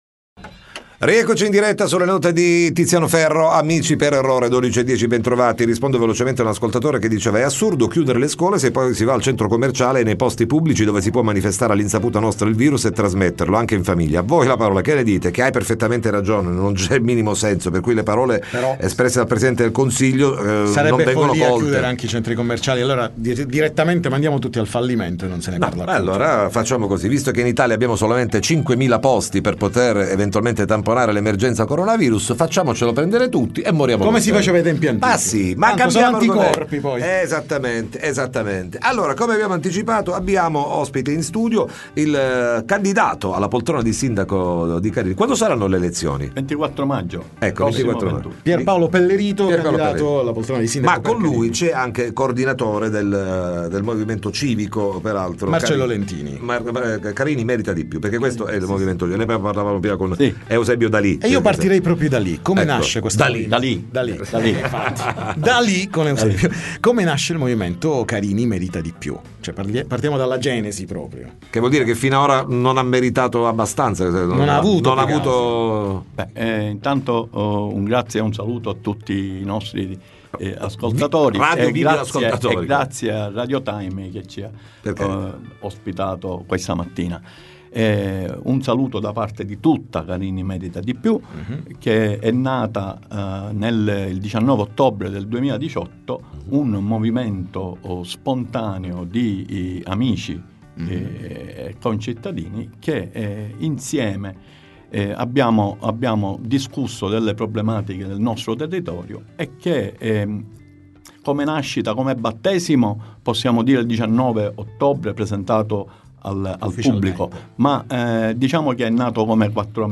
Time Magazine intervista